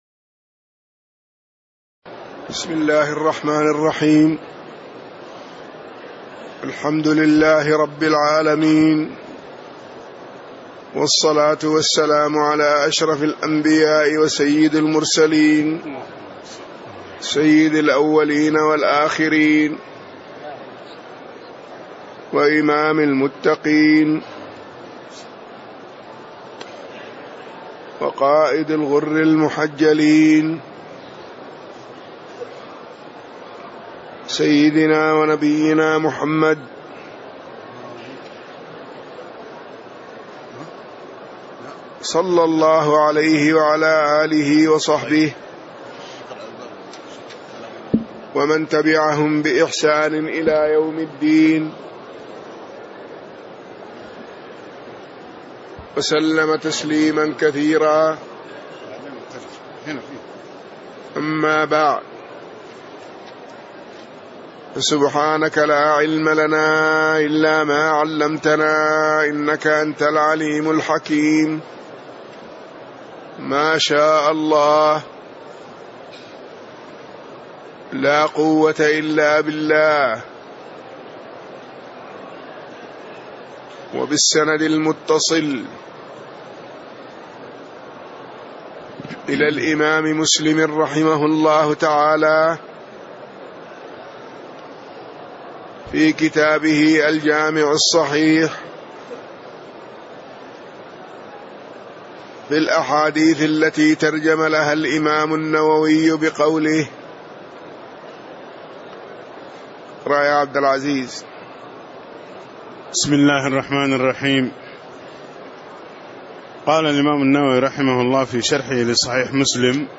تاريخ النشر ٤ محرم ١٤٣٧ هـ المكان: المسجد النبوي الشيخ